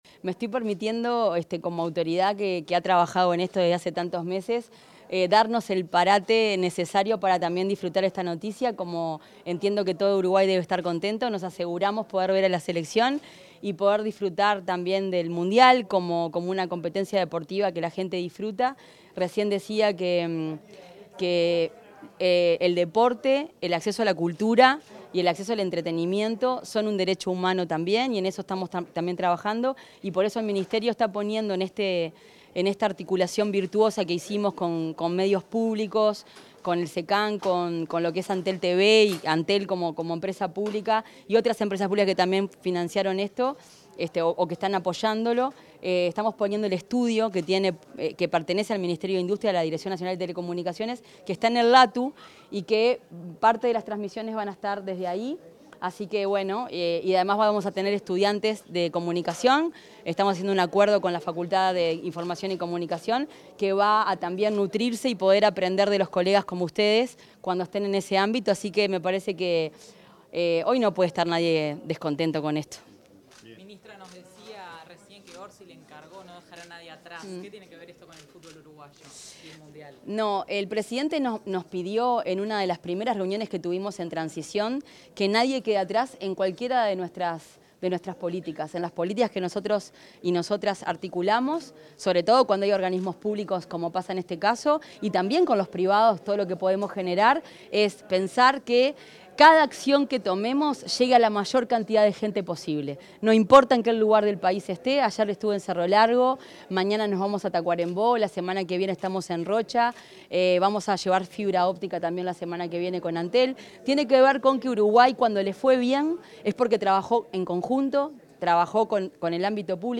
Declaraciones de la ministra de Industria, Fernanda Cardona
Declaraciones de la ministra de Industria, Fernanda Cardona 03/12/2025 Compartir Facebook X Copiar enlace WhatsApp LinkedIn En el lanzamiento de la transmisión de la Copa Mundial de la FIFA 2026, la ministra de Industria, Energía y Minería, Fernanda Cardona, dialogó con los medios de prensa.